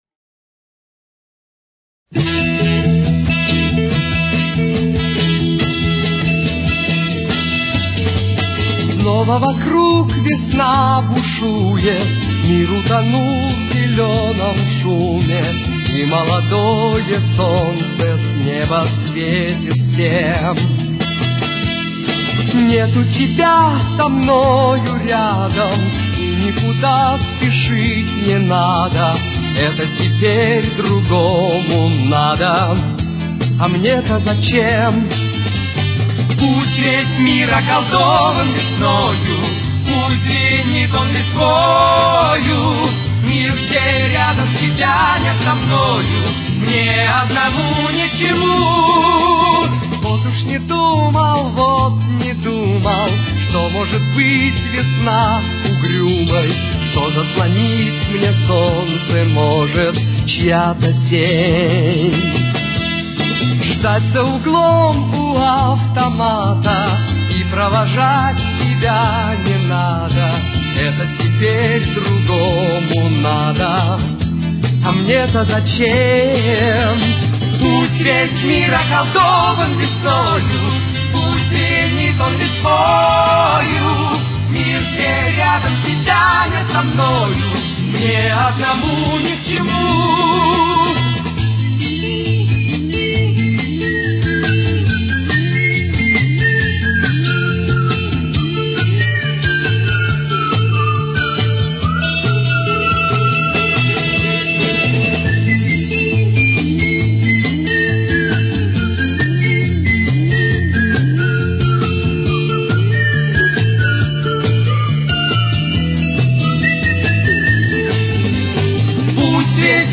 Ля минор. Темп: 143.